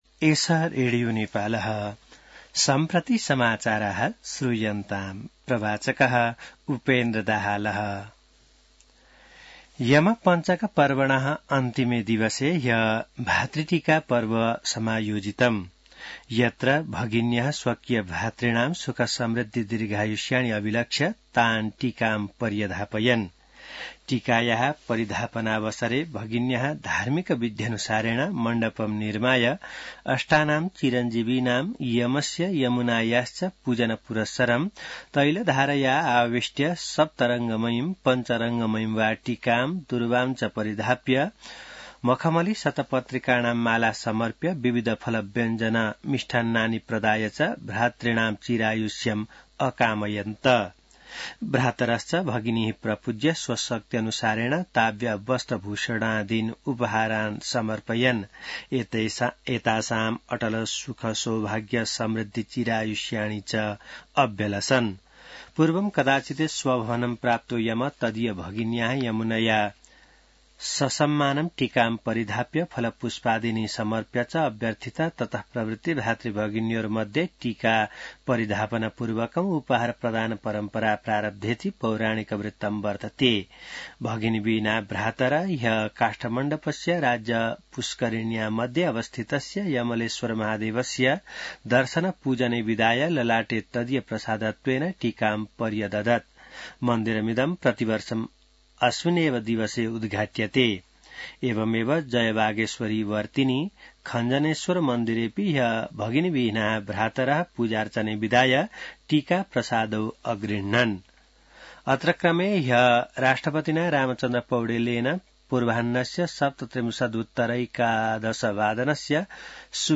संस्कृत समाचार : २० कार्तिक , २०८१